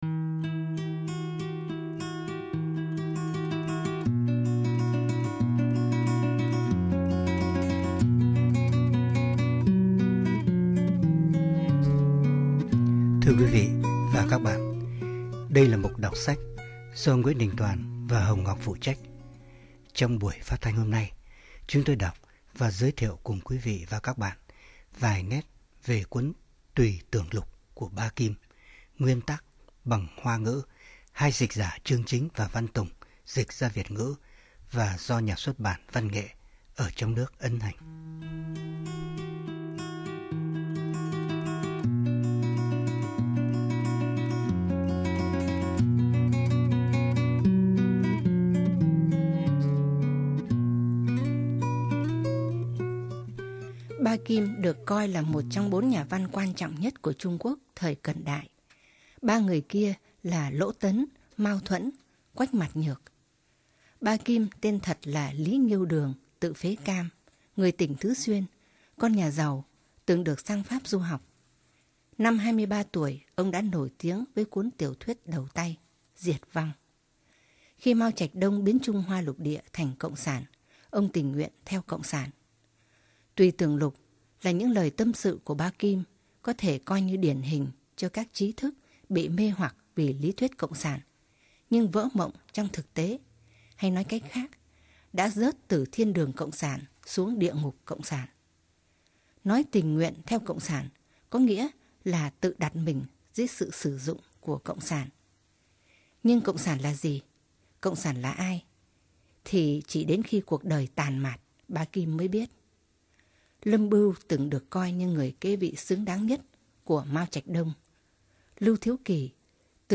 Biên sọan: Nguyễn Đình Tòan